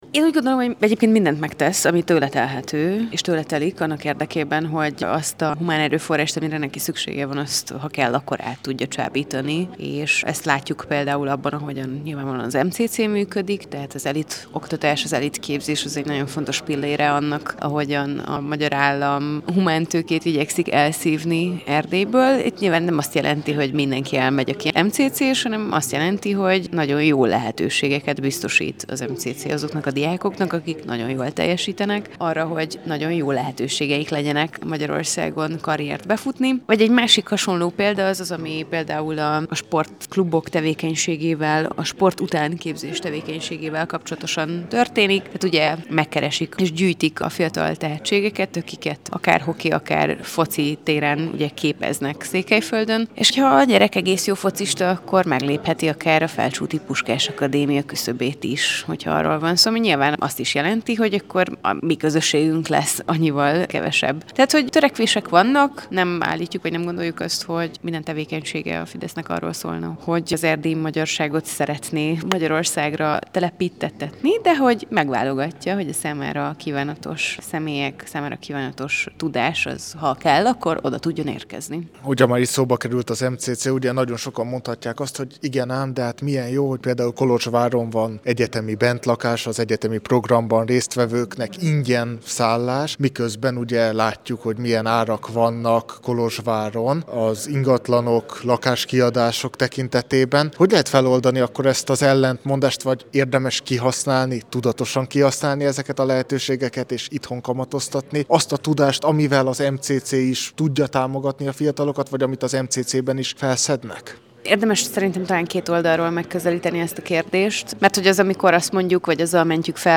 A csíkszeredai esemény után beszélgettünk az előadókkal.